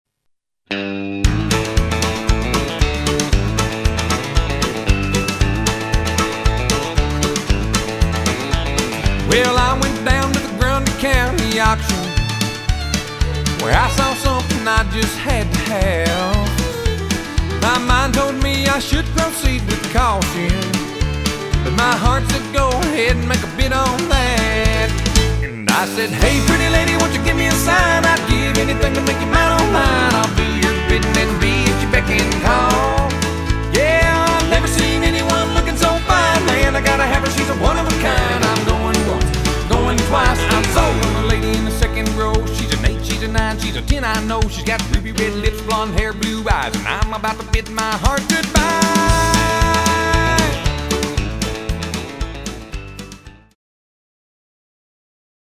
Country & Western Hits